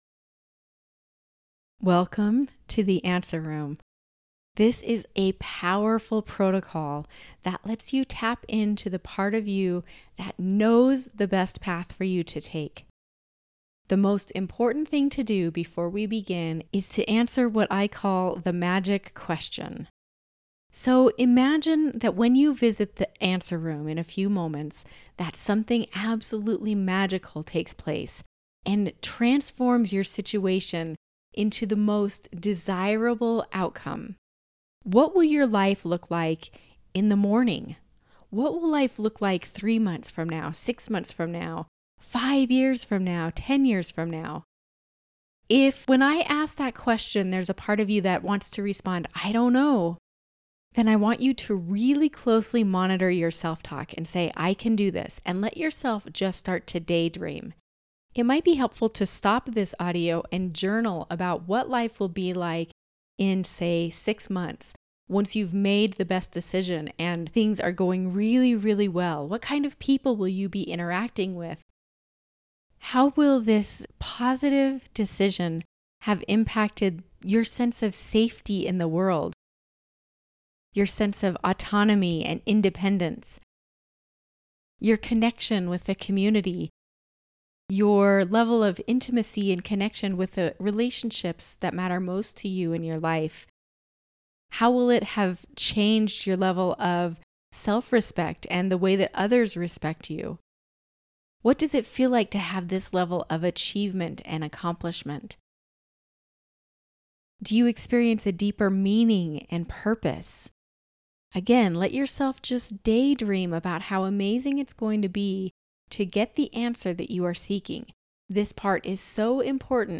This hypnotic audio is a powerful way to tap into Highest Wisdom and find the answer you've been searching for.